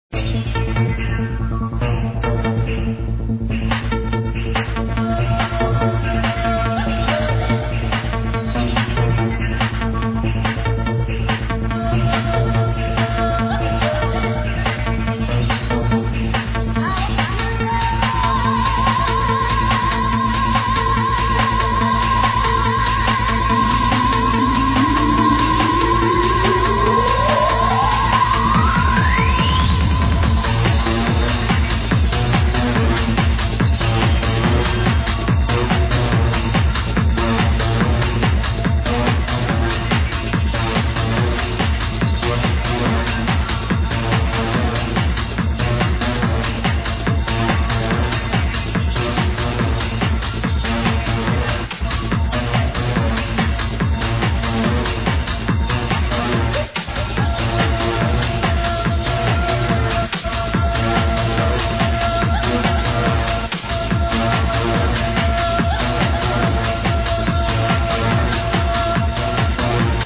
check this track out...a female vocal making Arabian sounds...moaning sorta...anyone know the name???
hmm.. that riff that comes in halfway into the sample sounds exactly like No Doubt - Hella good.